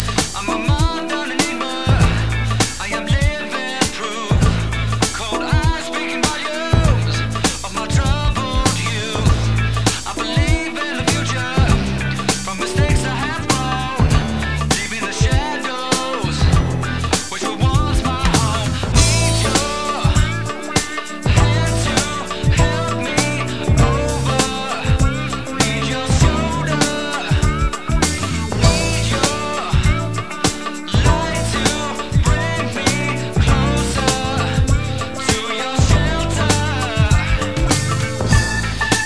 Ultra-cool Acid Jazz - Definitely my best buy of the year!